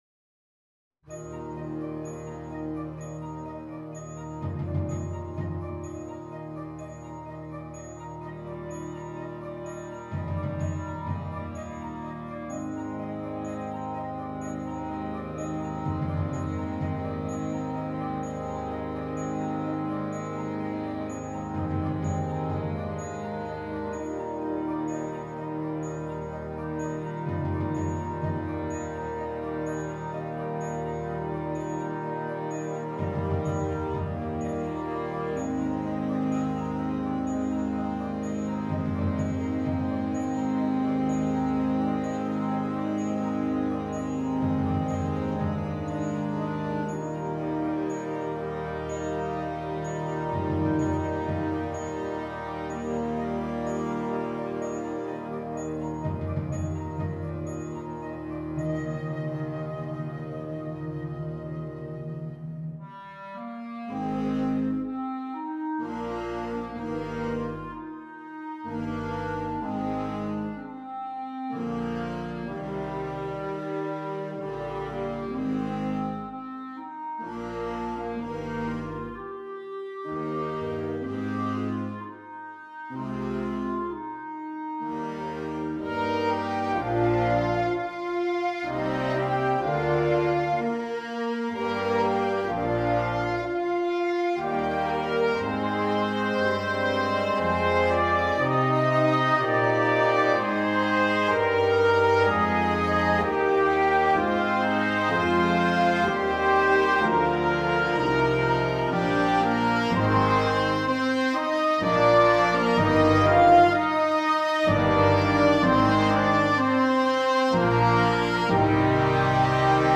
Gattung: Für Flexible Besetzung
Besetzung: Blasorchester